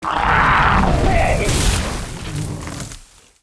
带人声激励的攻击zth070518.wav
通用动作/01人物/02普通动作类/带人声激励的攻击zth070518.wav
• 声道 單聲道 (1ch)